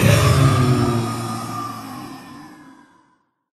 mob / blaze / death / hit.ogg
hit.ogg